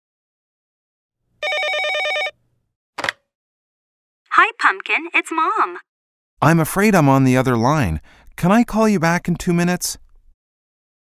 實境對話